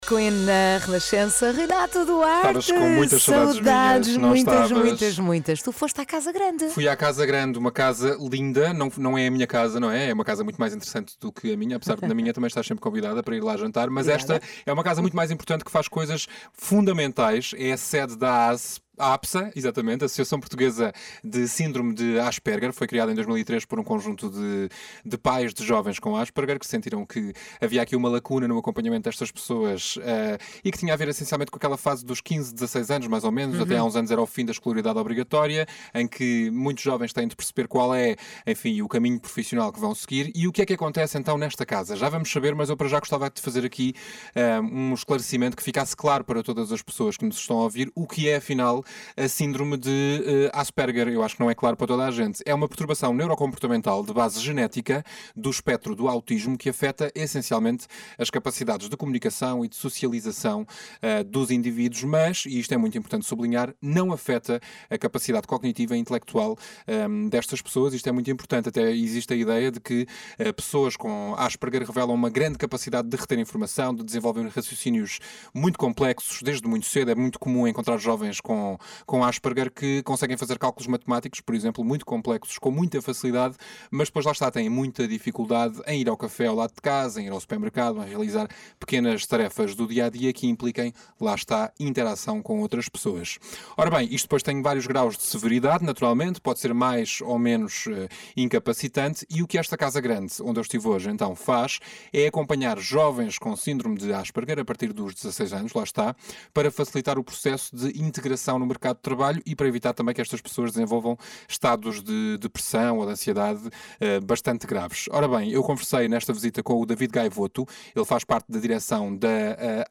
A Rádio Renascença veio até à Casa Grande e entrevistou-nos.